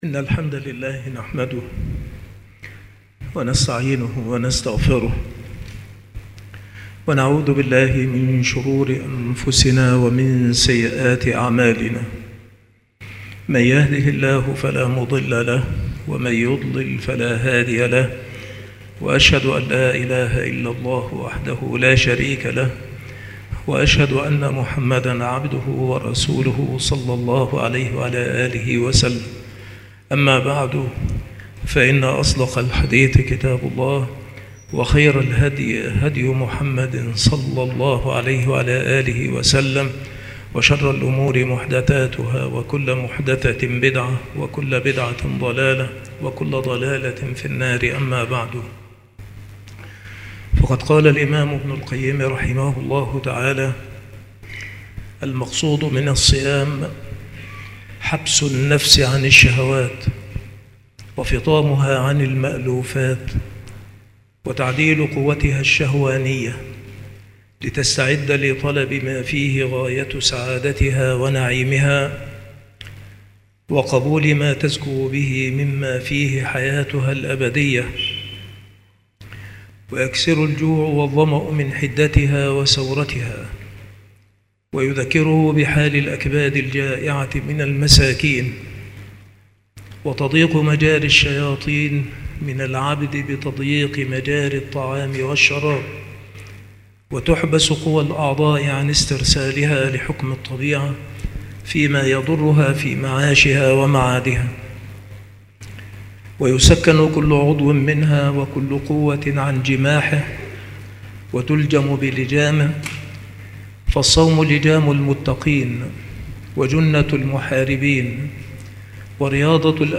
مكان إلقاء هذه المحاضرة بالمسجد الشرقي بسبك الأحد - أشمون - محافظة المنوفية - مصر عناصر المحاضرة